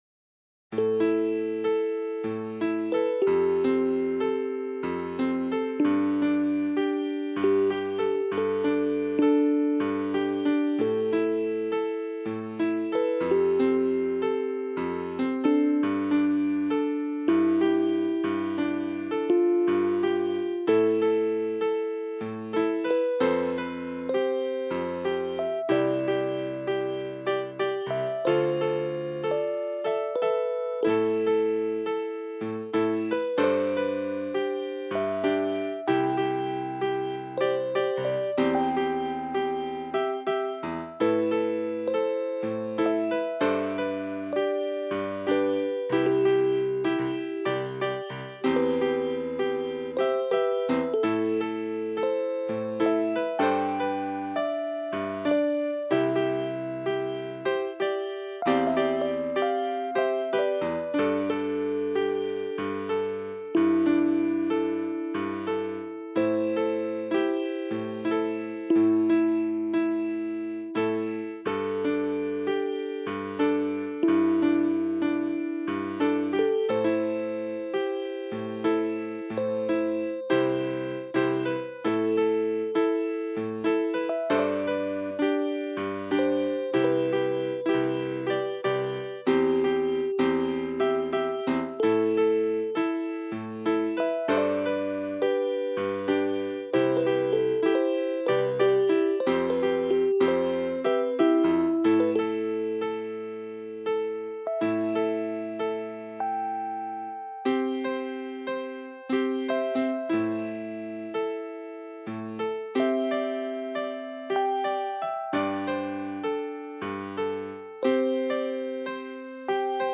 Another Piano Tune.